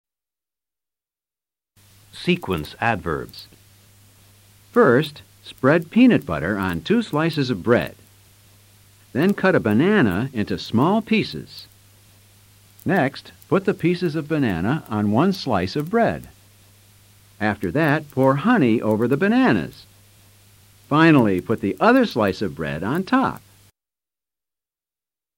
Escucha al profesor y presta atención al uso de los ADVERBIOS en sus ejemplos.